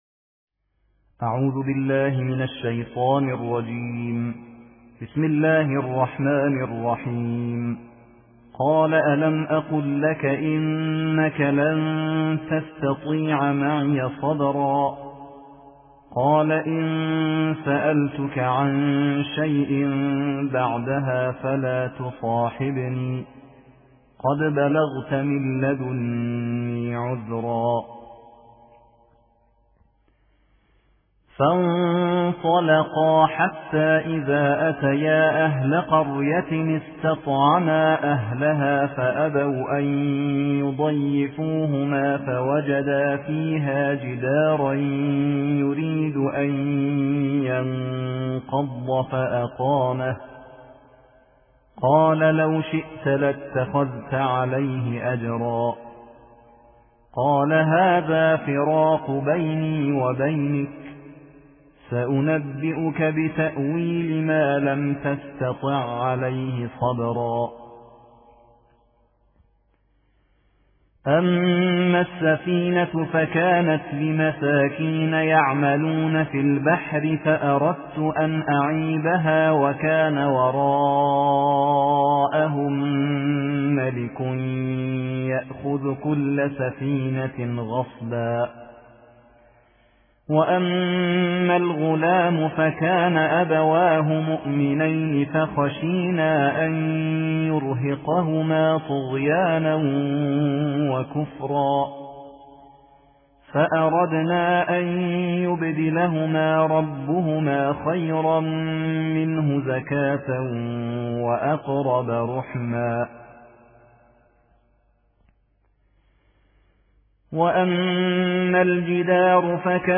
صوت/ ترتیل جزء شانزدهم قرآن